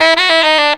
JAZZY D.wav